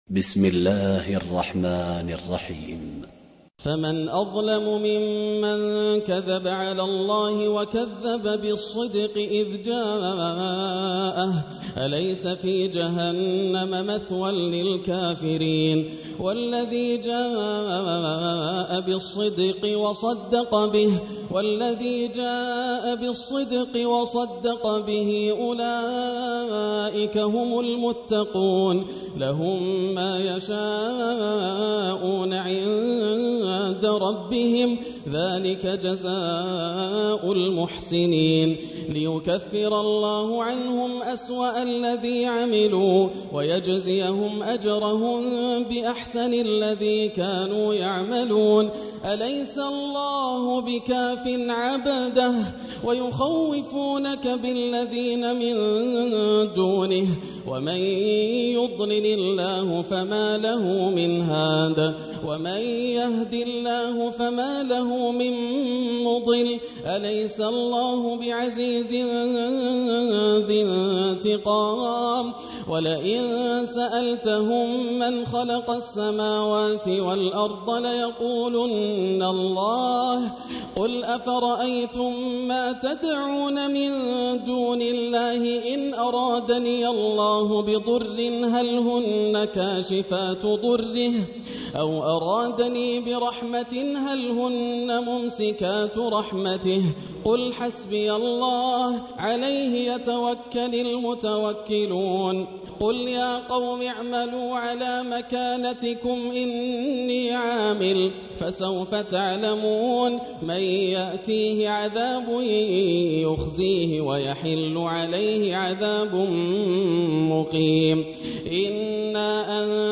تراويح ليلة 23 رمضان 1440هـ من سور الزمر (32-75) و غافر (1-52) Taraweeh 23 st night Ramadan 1440H from Surah Az-Zumar and Ghaafir > تراويح الحرم المكي عام 1440 🕋 > التراويح - تلاوات الحرمين